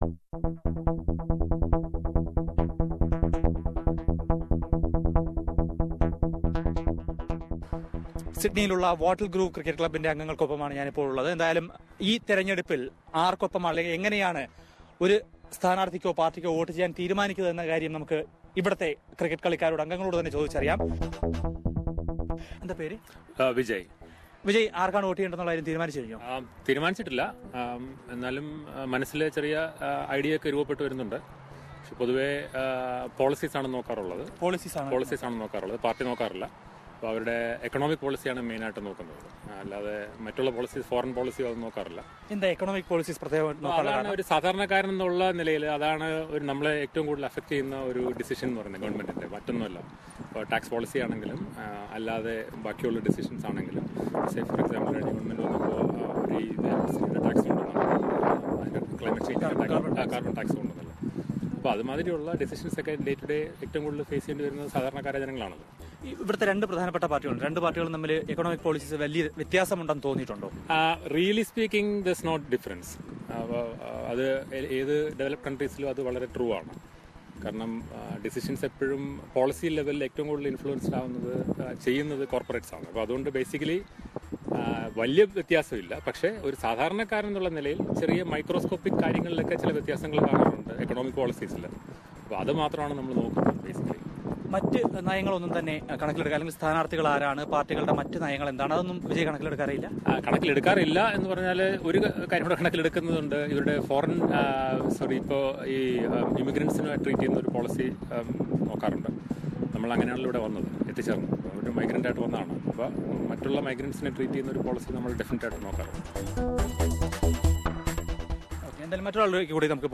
സിഡ്‌നിയിലെ വാട്ട്ല്‍ഗ്രൂവ് ക്രിക്കറ്റ് ക്ലബിലെ അംഗങ്ങള്‍ എസ് ബി എസ് മലയാളത്തോട് നിലപാടുകള്‍ പങ്കുവയ്ക്കുന്നു.